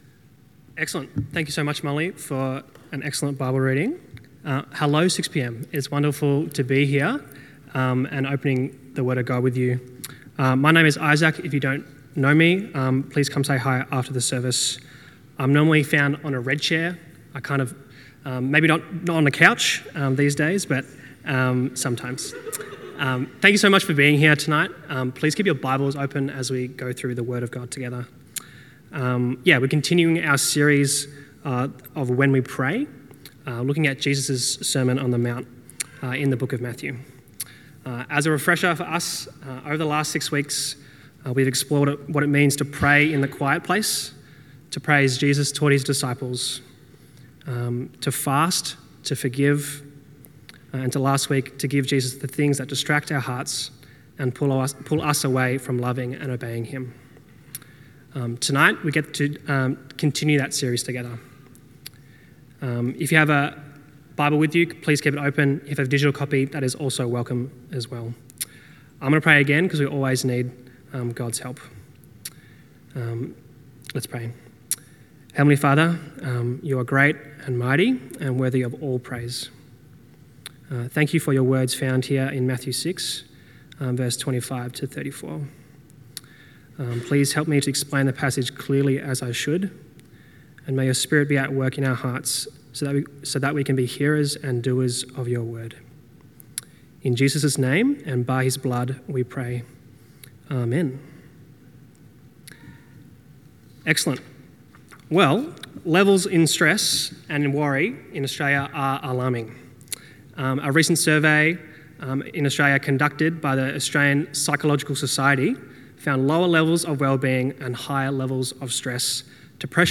Sermon
at Kew at 6pm